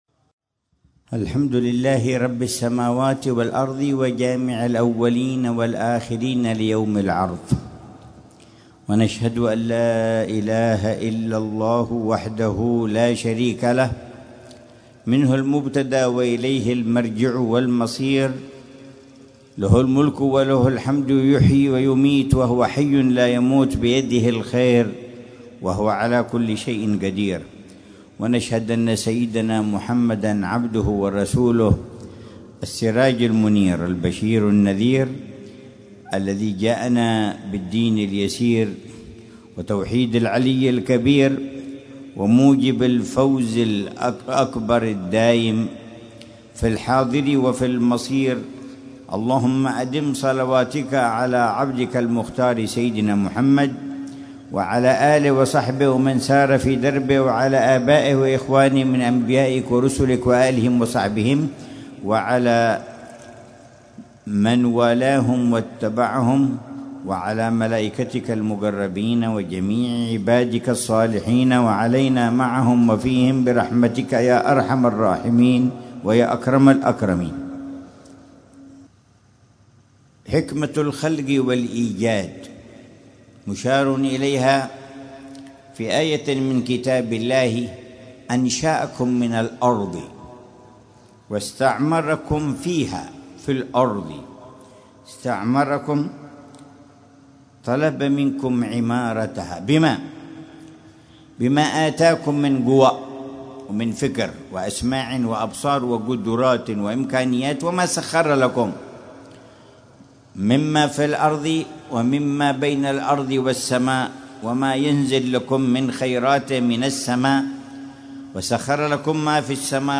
محاضرة العلامة الحبيب عمر بن محمد بن حفيظ في جلسة الجمعة الشهرية الـ63، في ساحة الأفراح بحارة دمون، ليلة السبت 21 ربيع الأول 1447هـ، بعنوان: